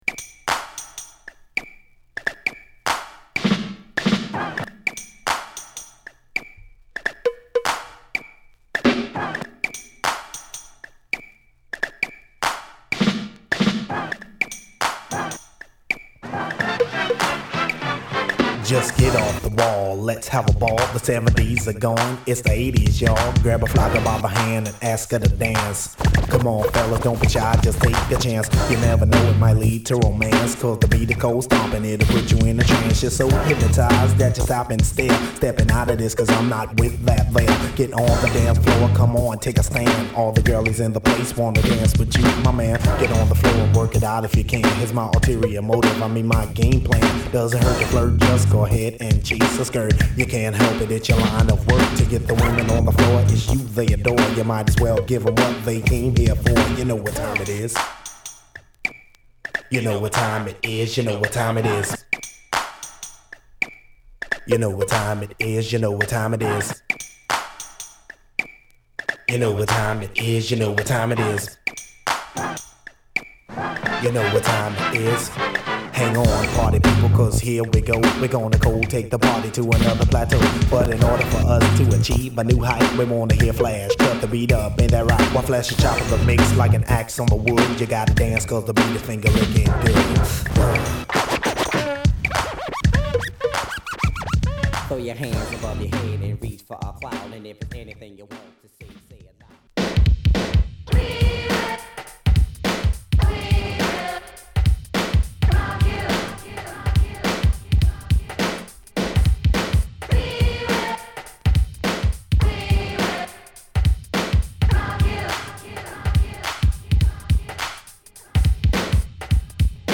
80's色全開のエレクトロな質感を楽しめる一枚！